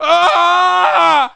WELDER-ELECTROCUTE1.mp3